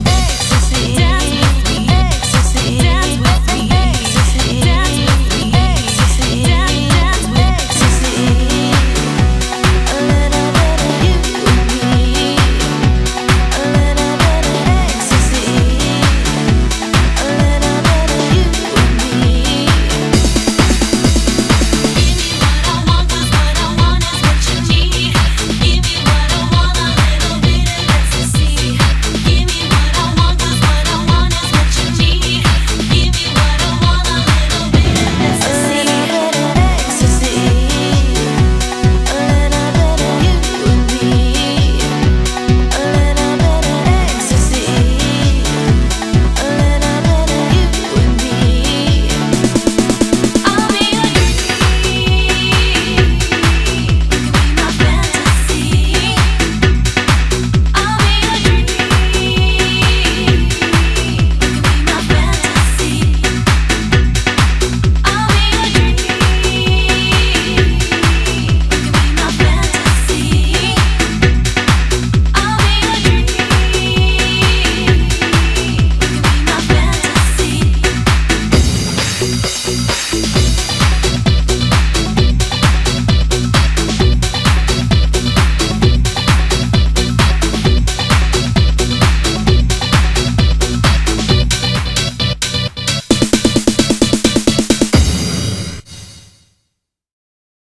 BPM132
Audio QualityPerfect (High Quality)
Genre: Euro House